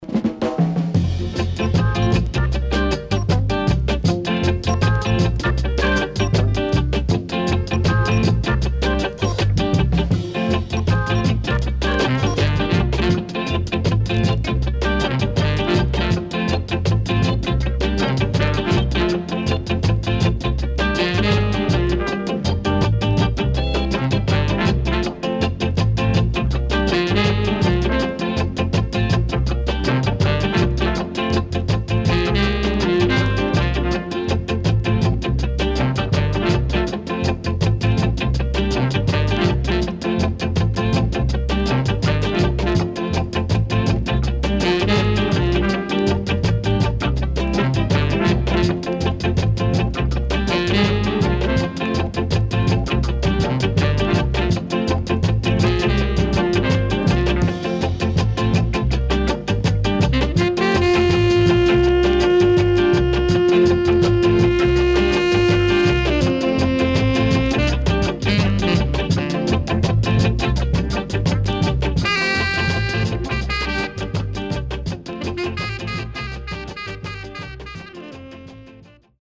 Very Nice Vocal & Early Reggae Inst.W-Side Great!!
SIDE A:少しチリノイズ入ります。